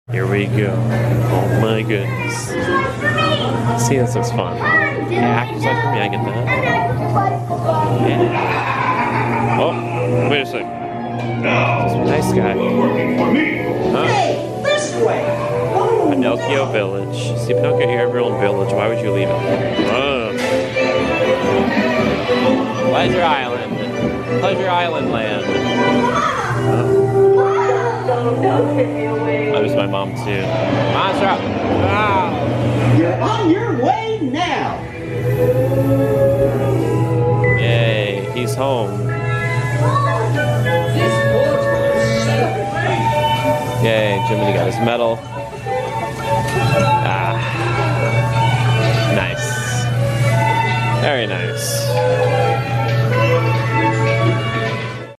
A truncated version of this tumultuous ride!